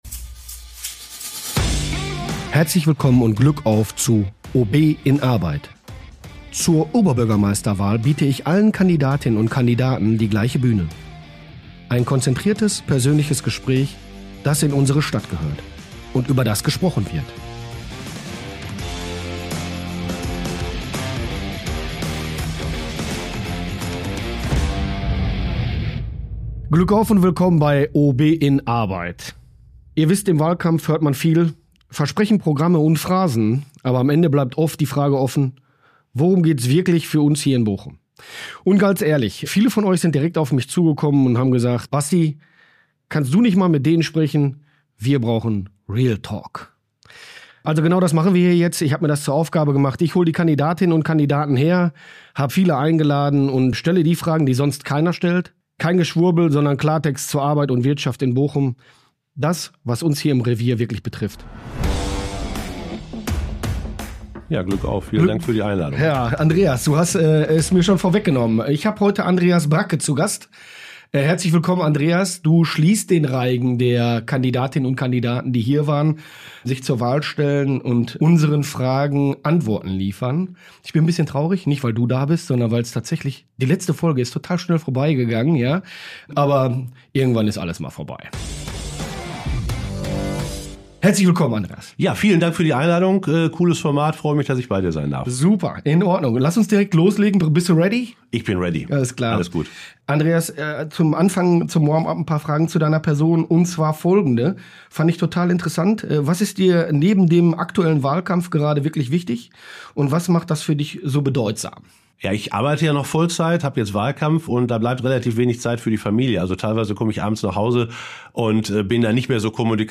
Zur Oberbürgermeisterwahl biete ich allen Kandidatinnen und Kandidaten die gleiche Bühne: ein konzentriertes, persönliches Gespräch, das in unserer Stadt gehört – und über das gesprochen wird.